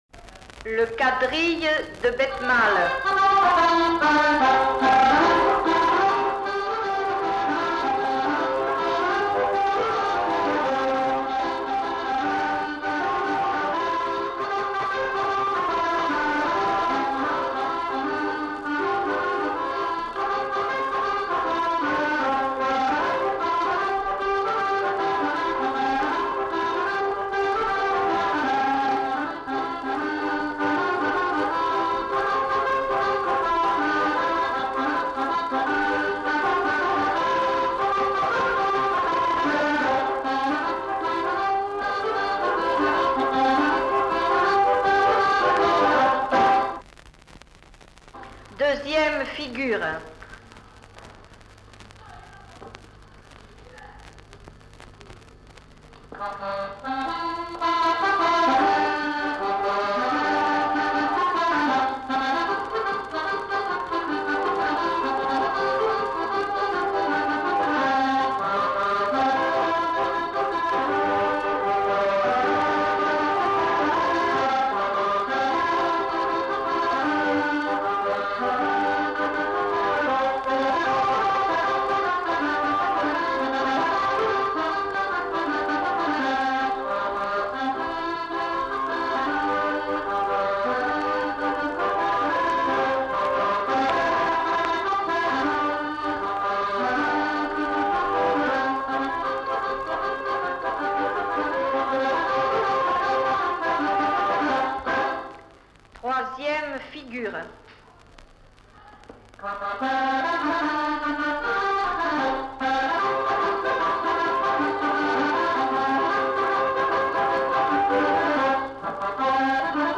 Aire culturelle : Couserans
Genre : morceau instrumental
Instrument de musique : accordéon chromatique
Danse : quadrille
Notes consultables : La dame qui annonce les morceaux n'est pas identifiée.